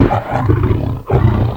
Monster Growl Talking